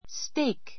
steak 小 A2 stéik ス テ イ ク （ ⦣ ea を ei エイ と発音することに注意） 名詞 ❶ ステーキ , ビーフステーキ （beefsteak） How would you like your steak?—Rare [Medium, Well-done], please.